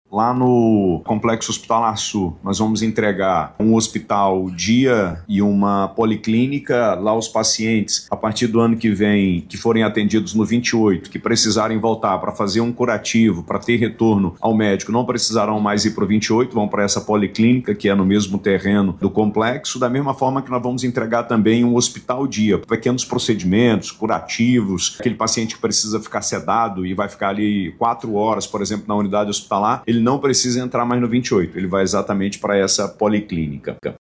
Questionado sobre possíveis reformas em outras Unidades de Saúde do Estado, o governador destacou algumas intervenções que são executadas na capital amazonense.